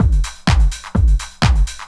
OSH Miltons Beat 1_127.wav